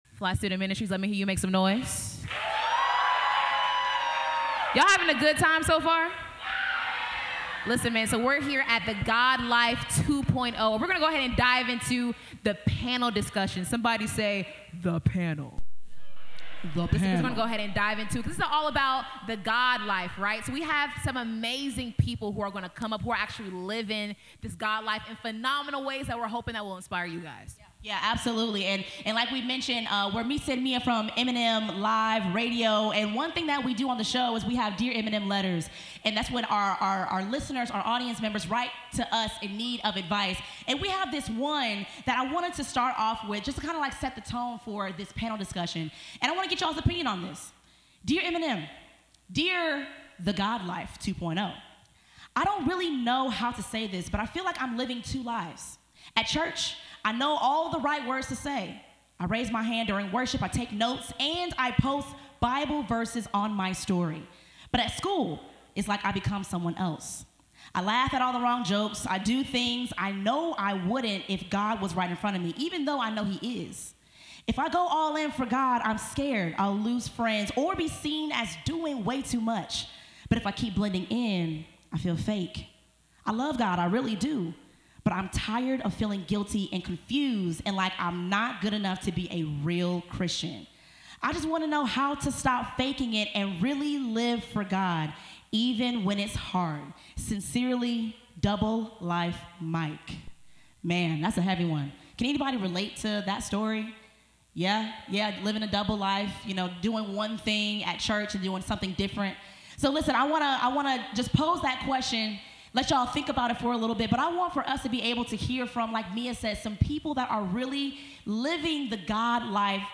Show #518 - Recorded LIVE at Life 2.0! | M&M Live Radio Panel Special
In this special live panel episode, we sat down with three incredible guests to talk faith, creativity, purpose, and impact: &#160